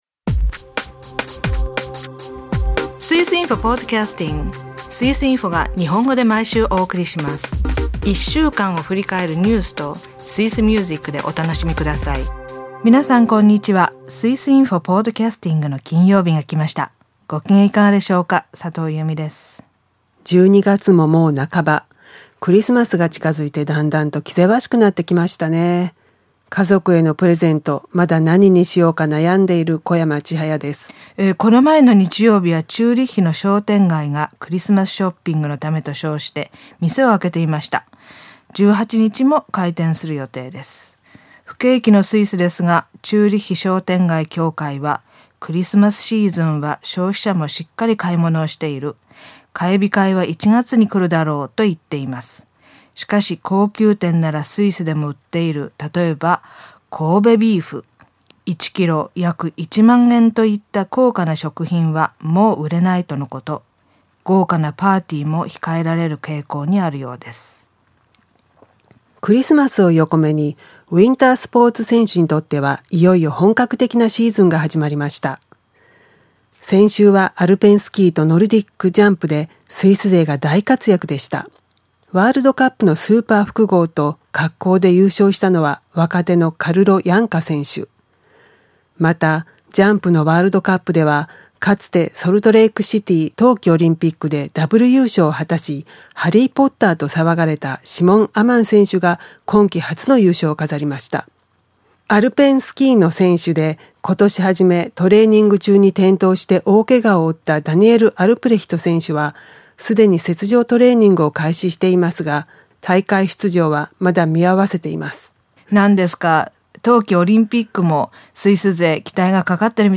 朗読「ブレーカーの自伝」は第5回です。